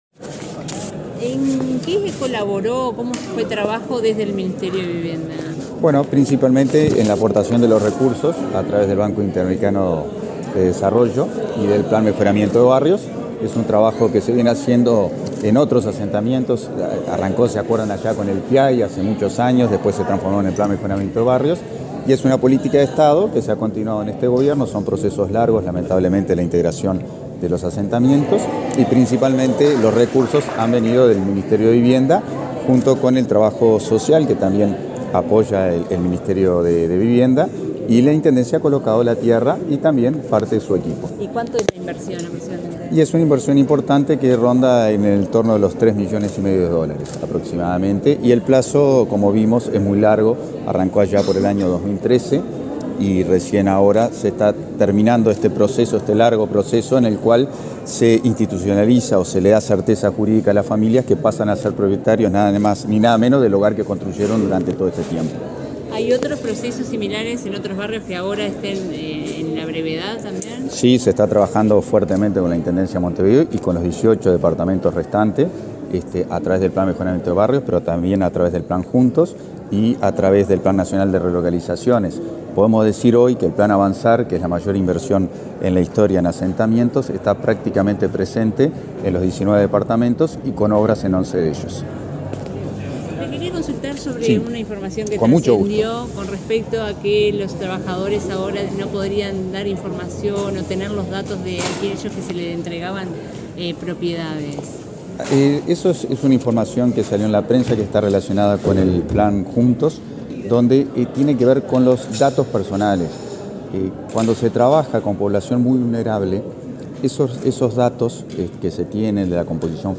Declaraciones del subsecretario de Vivienda, Tabaré Hackenbruch
Declaraciones del subsecretario de Vivienda, Tabaré Hackenbruch 13/09/2023 Compartir Facebook X Copiar enlace WhatsApp LinkedIn El subsecretario de Vivienda, Tabaré Hackenbruch, dialogó con la prensa, luego de participar, en Montevideo, en el acto de firma de escrituras de 40 viviendas del barrio Santa María de Piedras Blancas, que integran el Programa de Mejoramiento de Barrios.